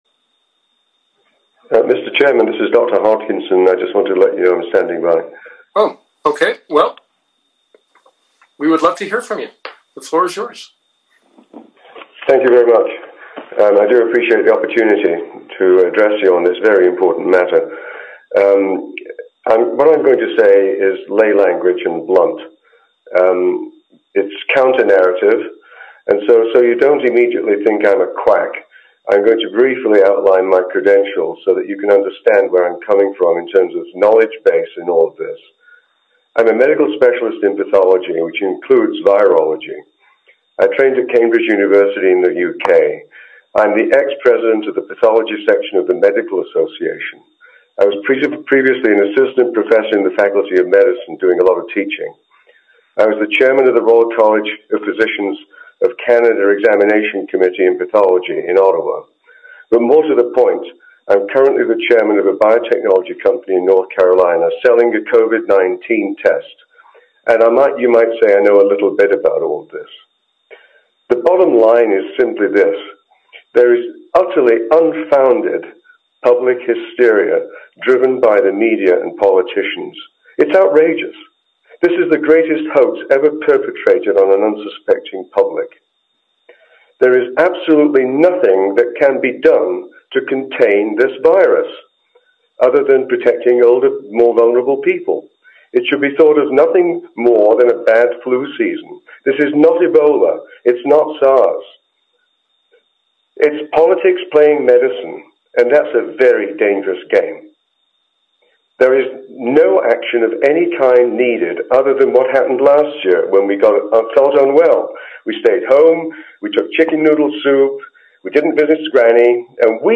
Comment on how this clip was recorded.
A recording of the zoom conference call was uploaded to YouTube.[2] Since it’s just an audio call recording anyway, here it is for the record: